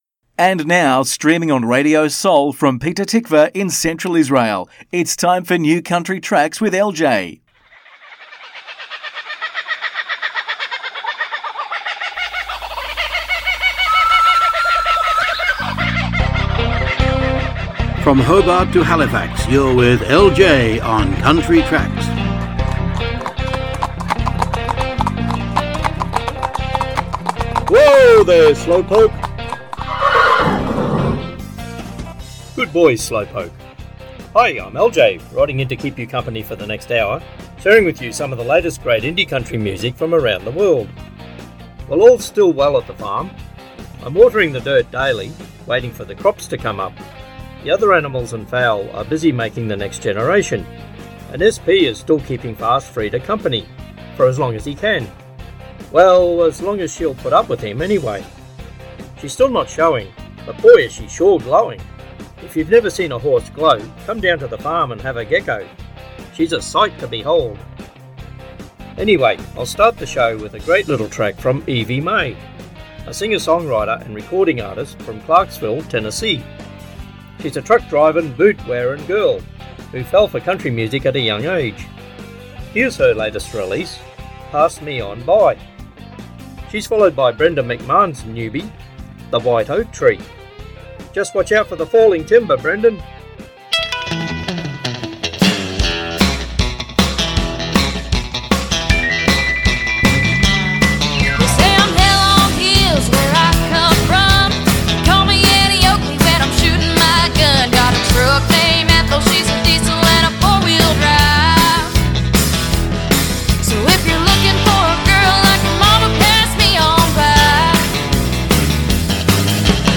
מוזיקת קאנטרי ואינדי עולמית - התכנית המלאה 29.11.24